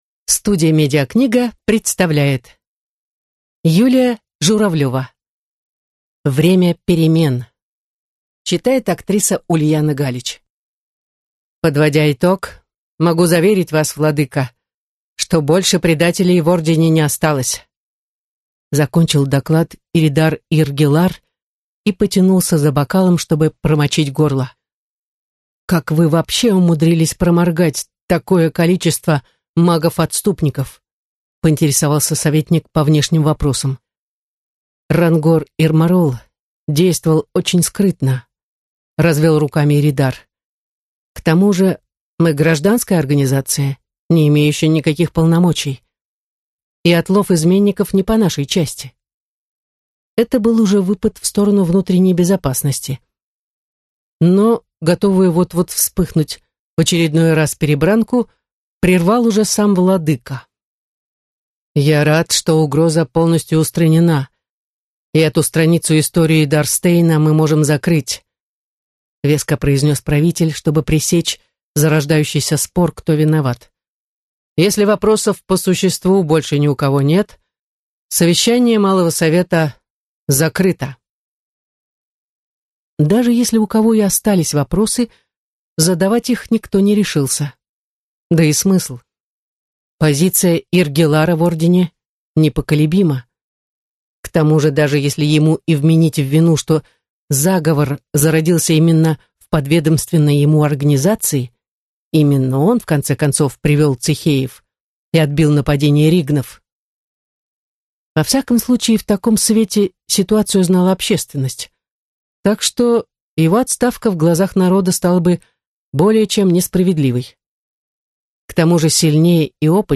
Аудиокнига Время перемен | Библиотека аудиокниг